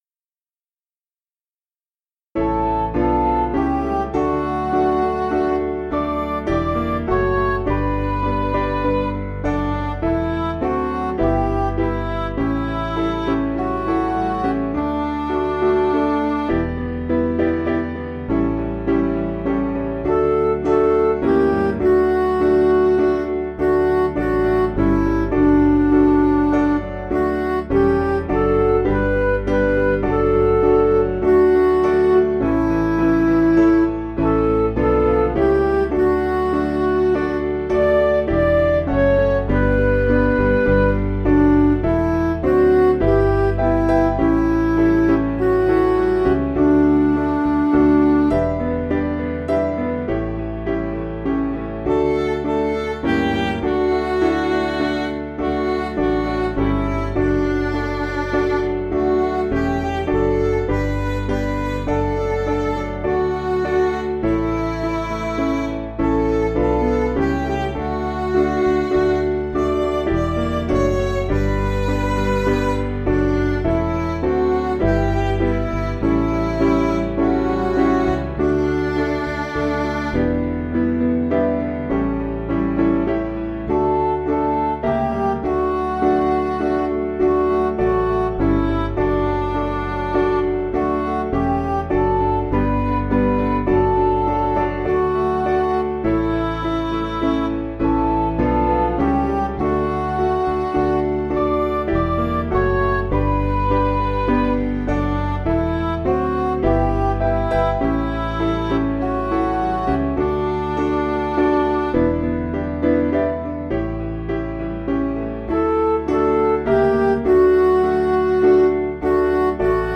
Vocals and Band   266.1kb Sung Lyrics 3.2mb